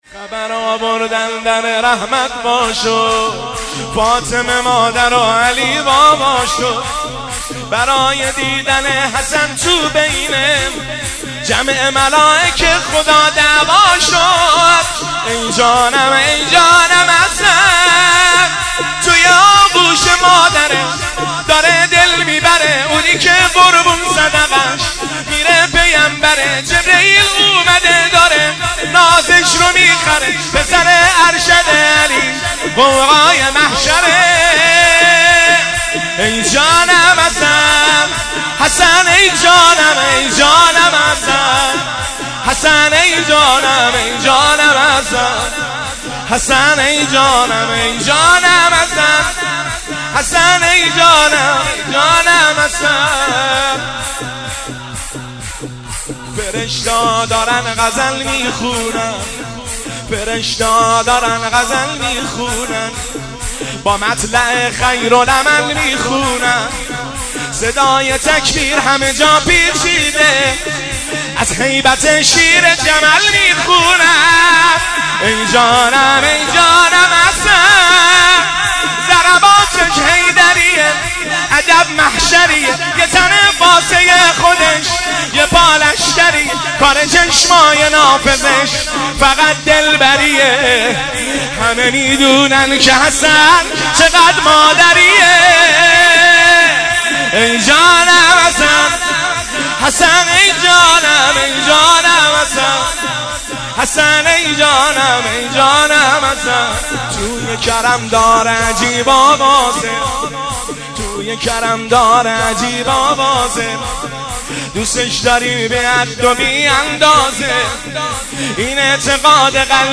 سرود میلاد امام حسن مجتبی (ع) 1402